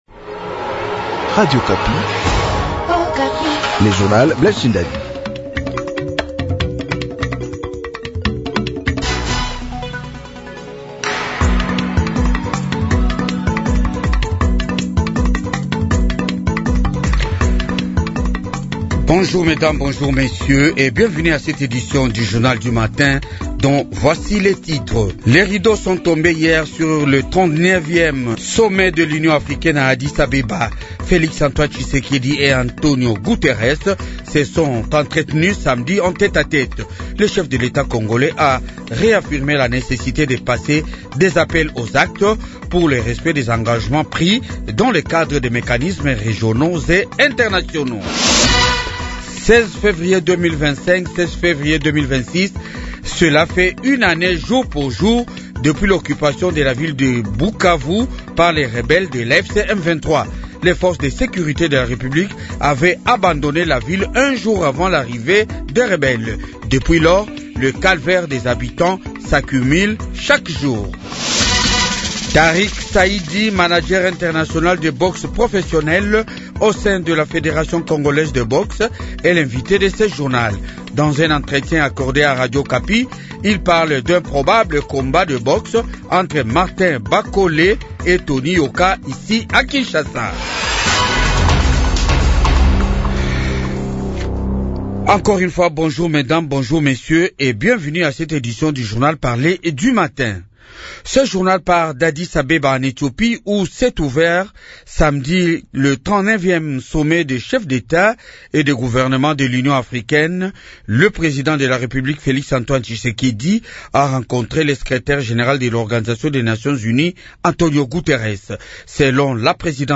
Journal du matin 7h